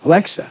synthetic-wakewords
ovos-tts-plugin-deepponies_Joe Biden_en.wav